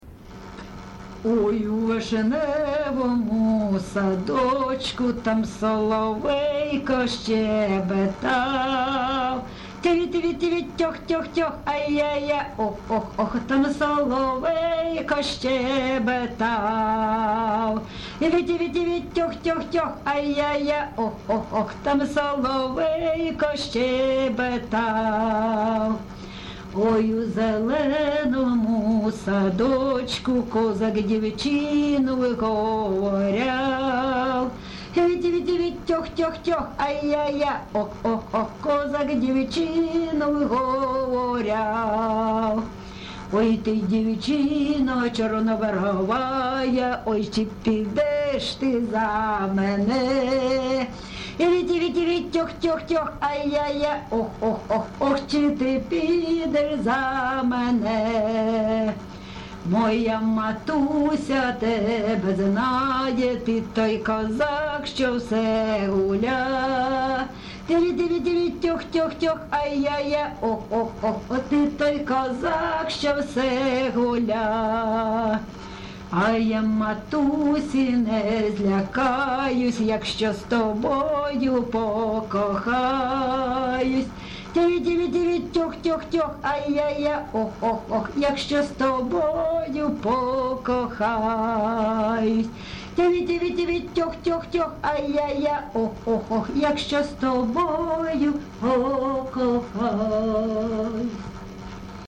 ЖанрПісні літературного походження, Сучасні пісні та новотвори
Місце записус. Лозовівка, Старобільський район, Луганська обл., Україна, Слобожанщина